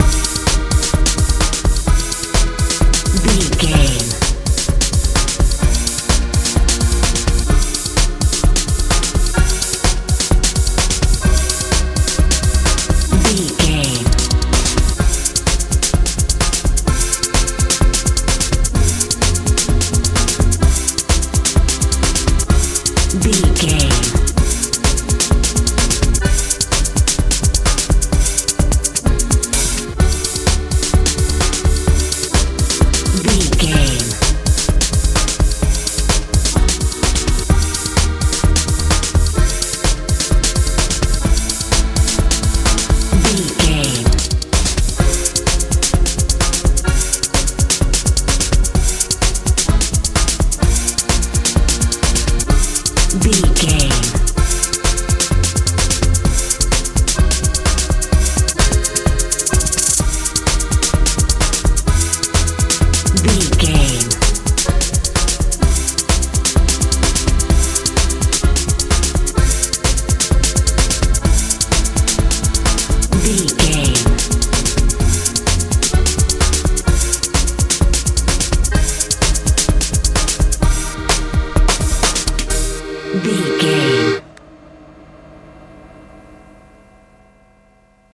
euro dance feel
Ionian/Major
A♭
Fast
magical
mystical
accordion
bass guitar
drums
synthesiser
strange